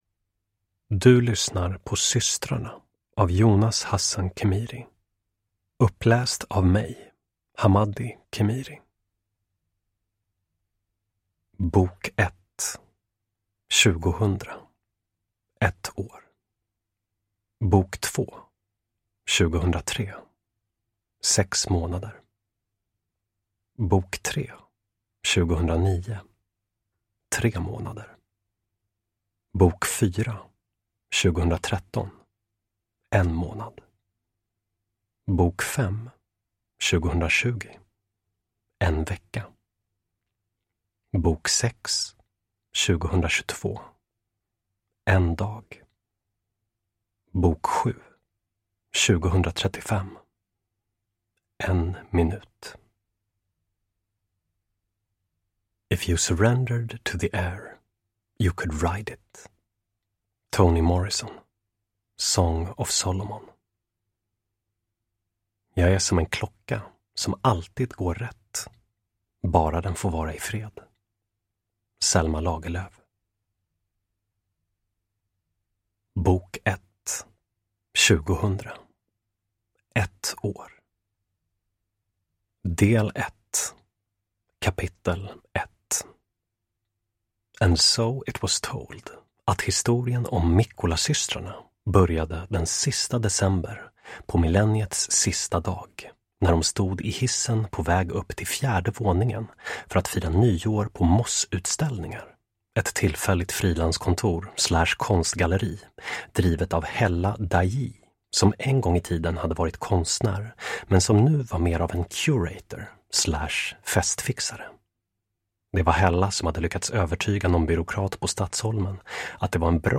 Systrarna – Ljudbok – Laddas ner